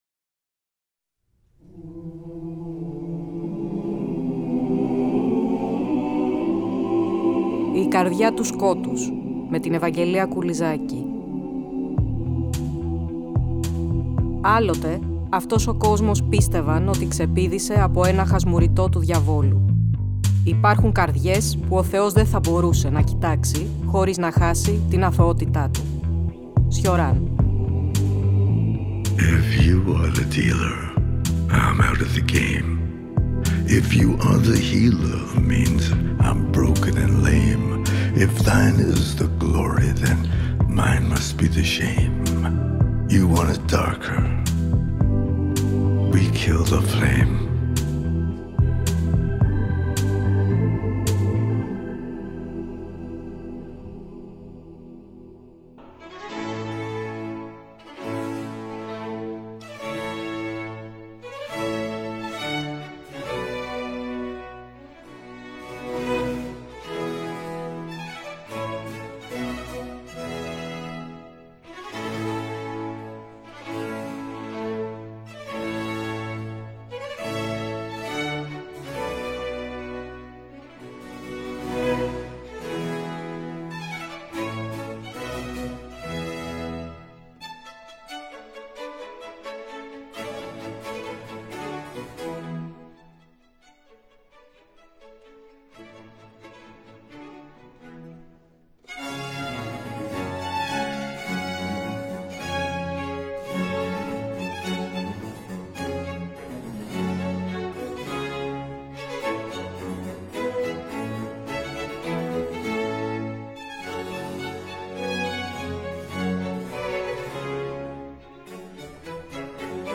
Ακούστε το 1ο επεισόδιο του νέου Κύκλου της εκπομπής, που μεταδόθηκε την Κυριακή 26 Απριλίου από το Τρίτο Πρόγραμμα.